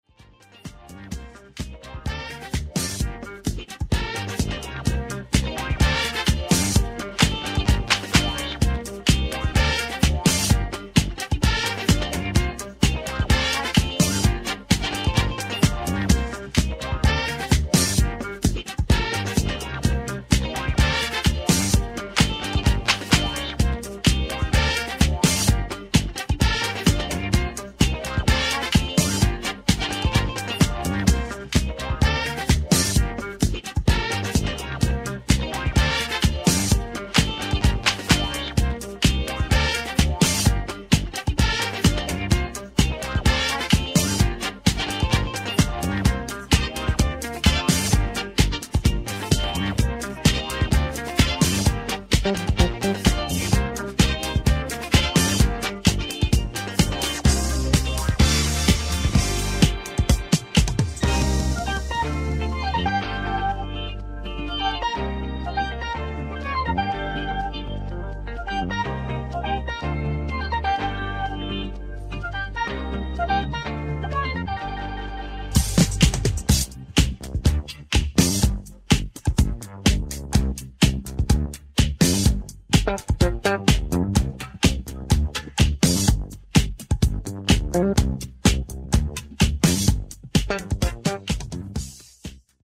主に70sディスコ・ブギー路線のレア楽曲を捌いたエディット集となっています。
いずれもポジティヴなエネルギーで溢れた内容です！